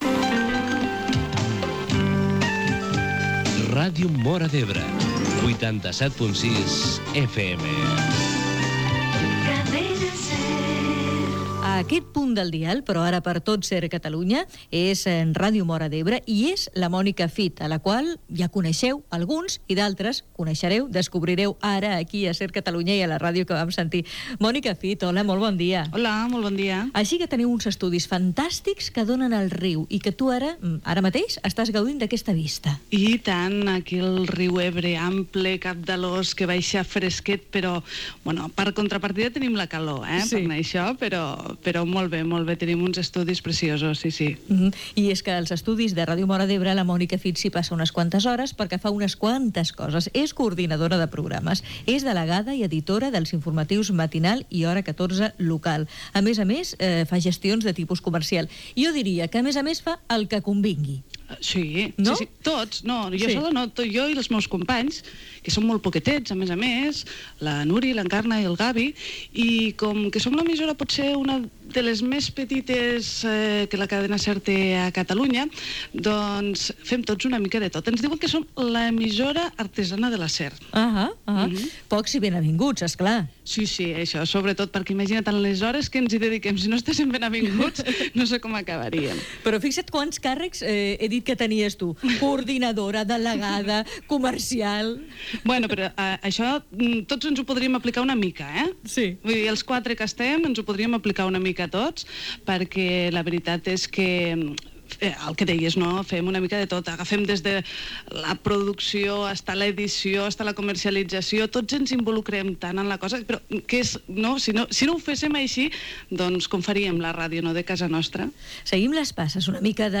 Indicatiu de Ràdio Móra d'Ebre.